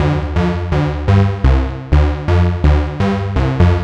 cch_synth_warehouse_125_C.wav